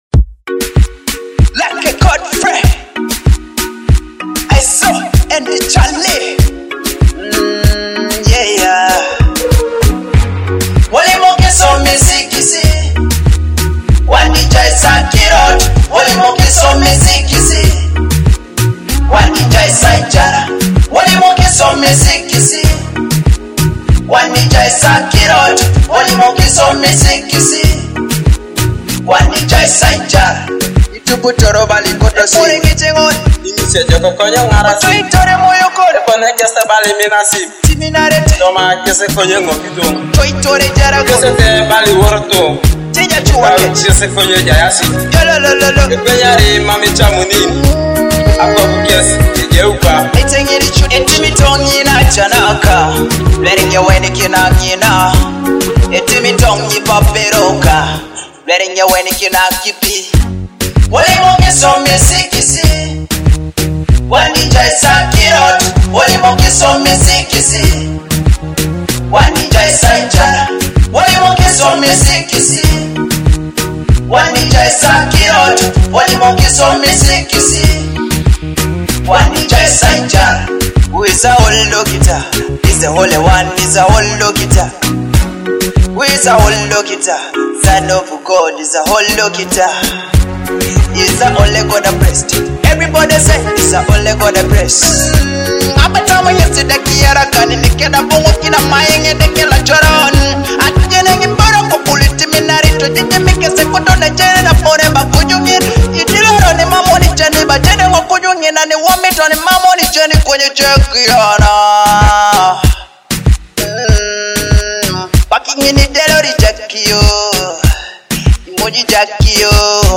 gospel dancehall
a fresh gospel dancehall track in Ateso